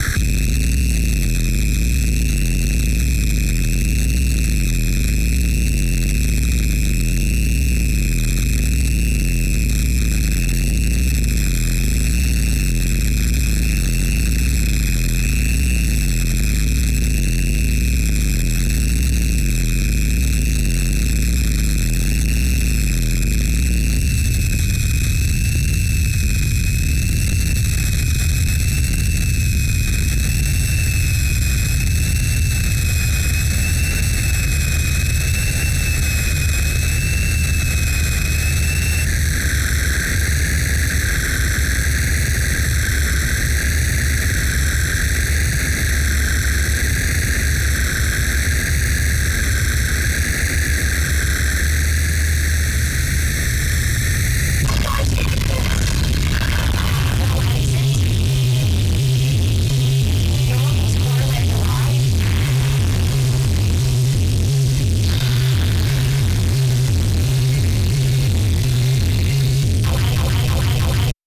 noise, gabber, digital hardcore, breakcore, ,